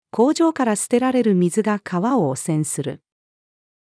工場から捨てられる水が川を汚染する。-female.mp3